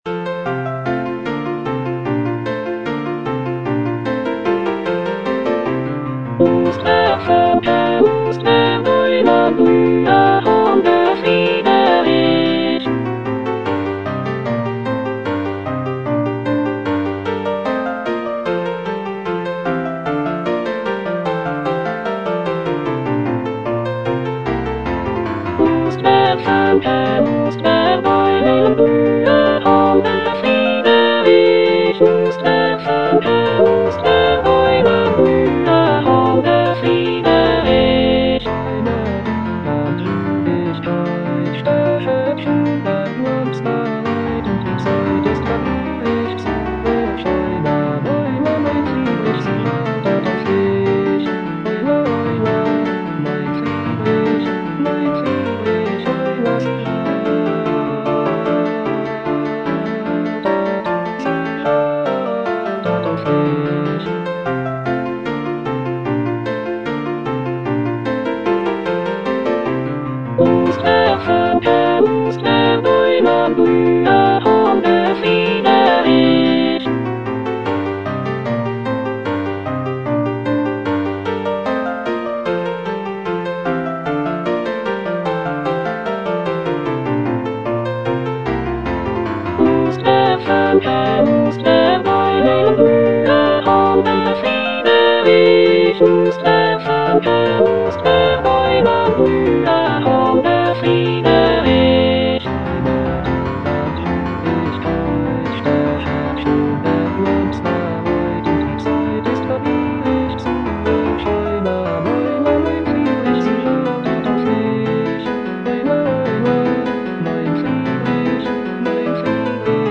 Choralplayer playing Cantata
The cantata features a celebratory and joyful tone, with arias and recitatives praising the prince and his virtues. It is scored for soloists, choir, and orchestra, and showcases Bach's mastery of counterpoint and vocal writing.